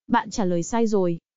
Am-thanh-ban-tra-loi-sai-roi_pp.wav